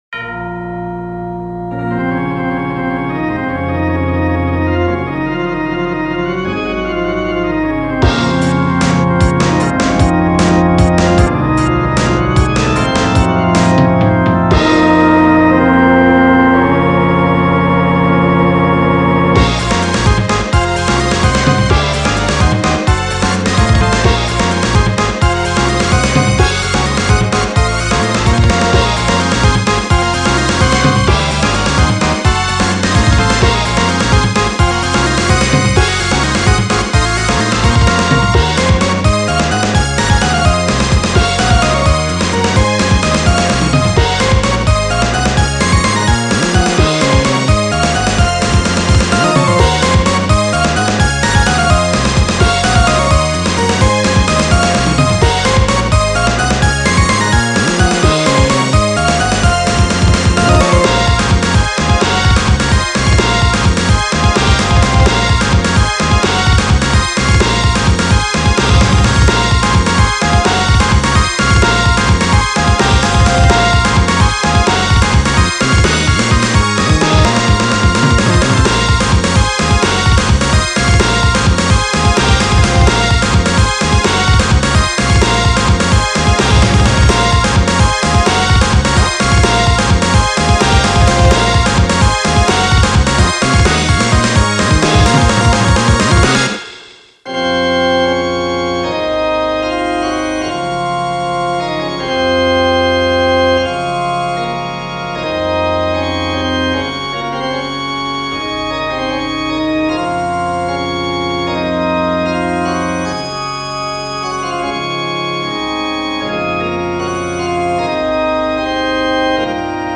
ジャンル16-Bit、ゴシック、クラシック
使用例ボス戦闘曲、ラスボスへの演出
BPM７６→１２８
スーファミ風(16-Bit music)